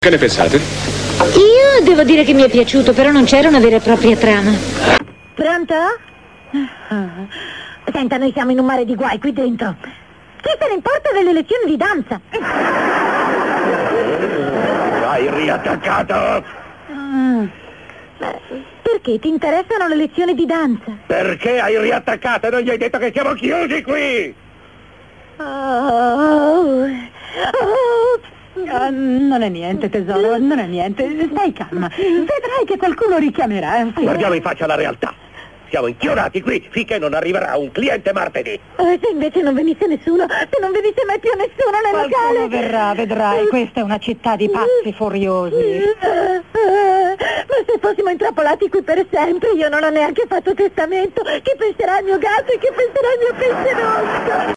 nel telefilm "Alice", in cui doppia Beth Howland.